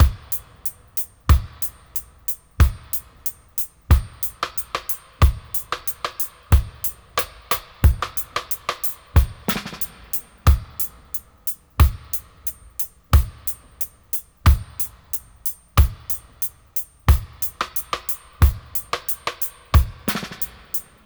90-FX-05.wav